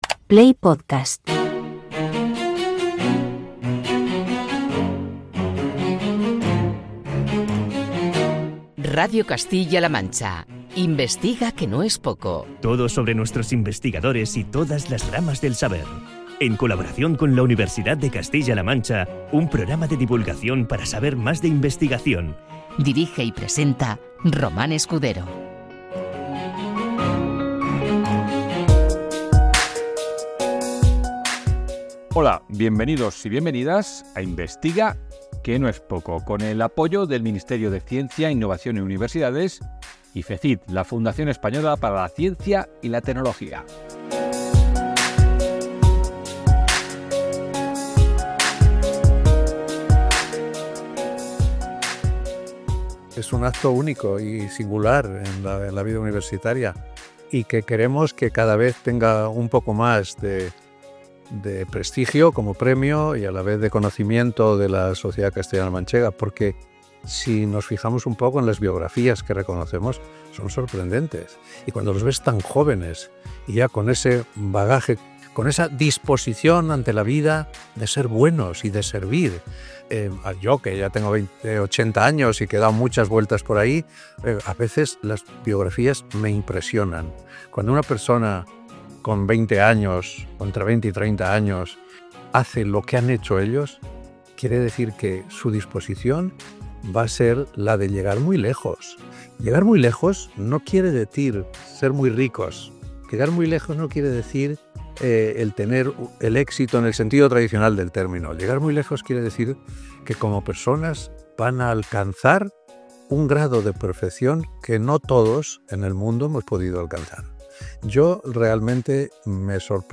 Charlamos con los Reconocidos del Consejo Social. Estudiantes premiados por su excelencia universitaria, docentes por su innovación, destacados investigadores, personal de la universidad por su dedicación a la institución, e instituciones y personas que colaboran con la UCLM.